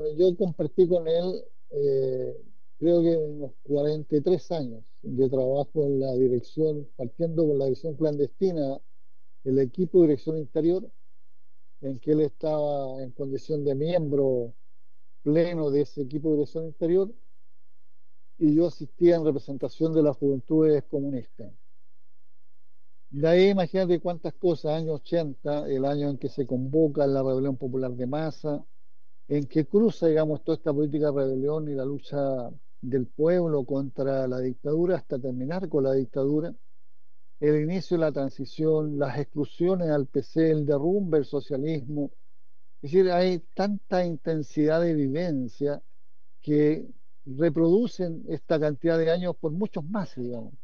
Desde la romería que se realizó el domingo en el Cementerio General en homenaje del ex presidente del Partido Comunista, Guillermo Teillier, el actual timonel de la colectividad, Lautaro Carmona, compartió palabras de reconocimiento.